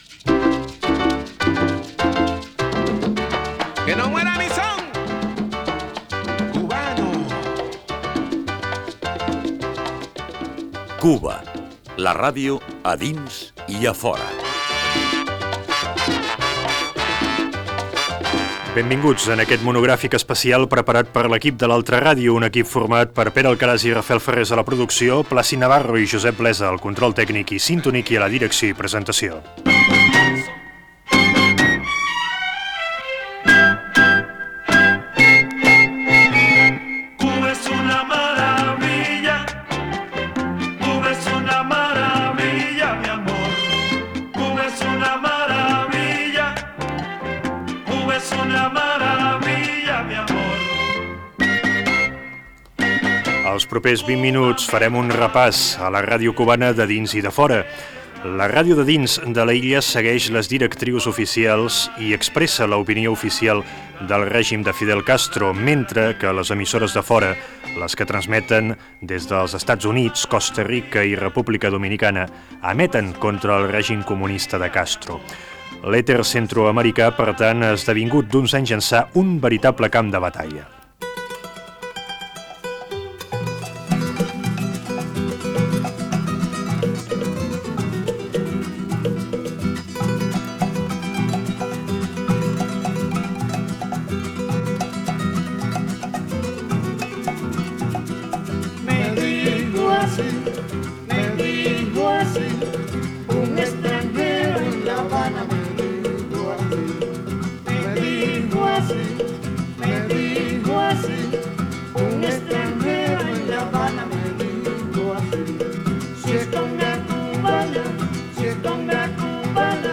Reportatge sobre la història de l radiodifusió cubana i les emissores que emeten des de l'exterior cap a Cuba